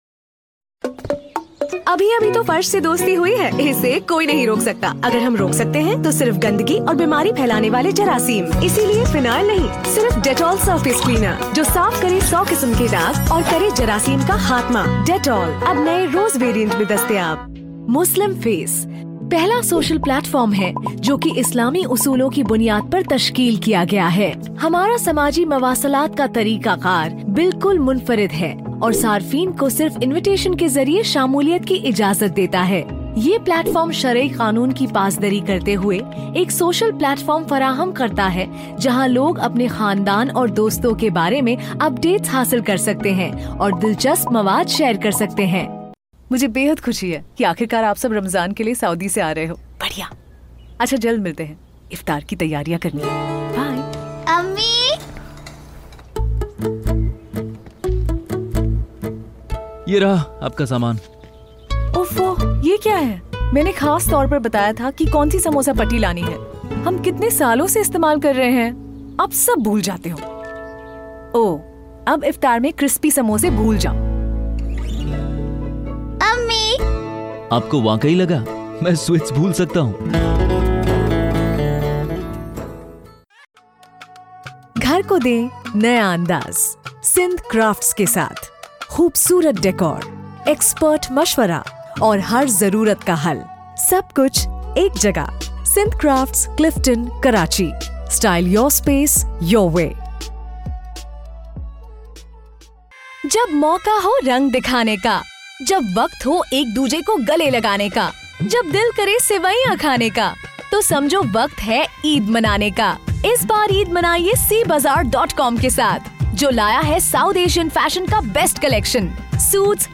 Autorisierend
Glaubhaft
Warm